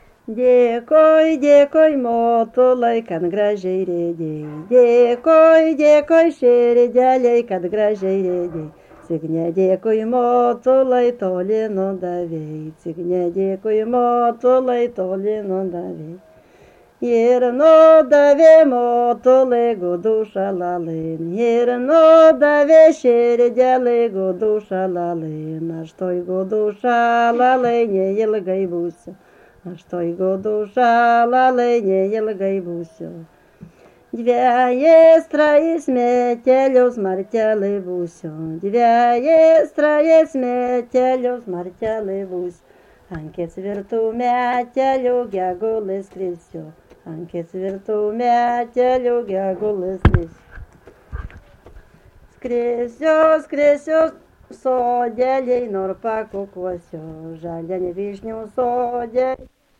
Subject daina
Erdvinė aprėptis Druskininkai
Atlikimo pubūdis vokalinis